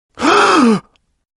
Download Gasp sound effect for free.
Gasp